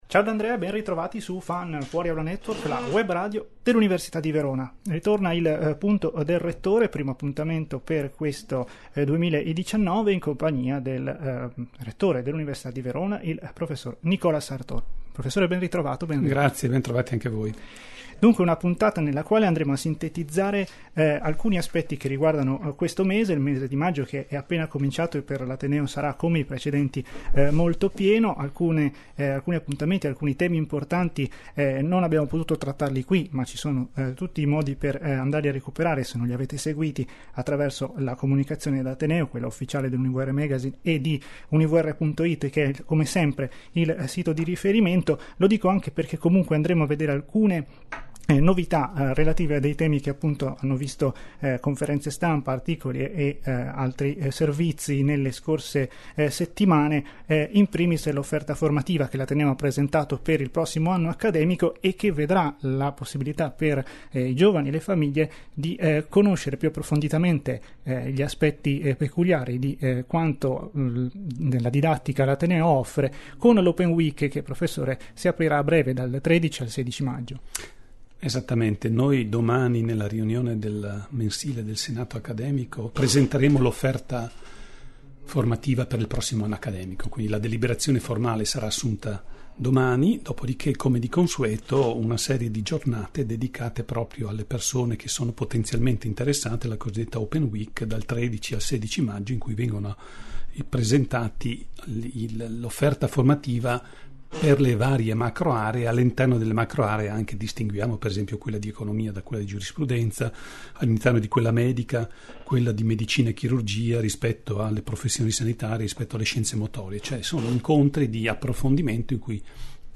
Ogni mese il Magnifico rettore dell’Università di Verona, prof. Nicola Sartor, sarà ospite degli studi di FAN per discutere di temi di atttualità.